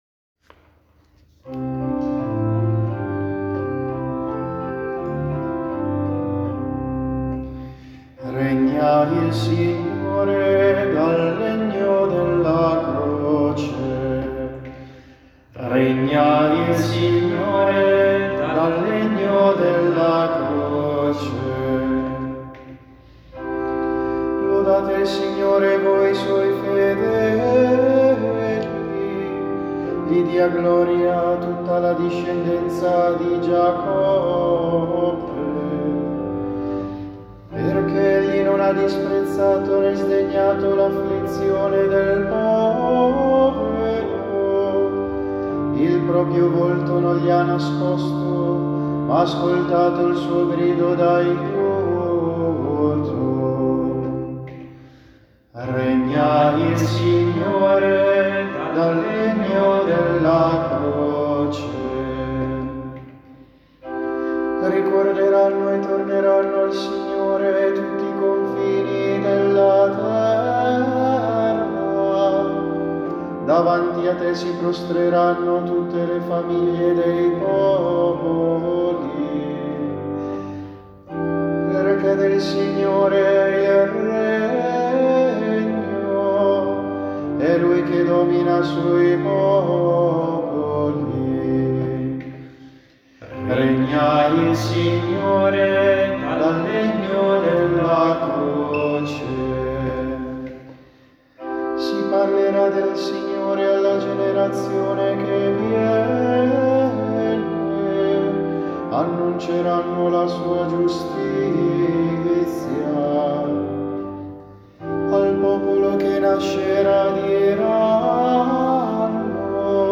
Esecuzione decanato Vigentino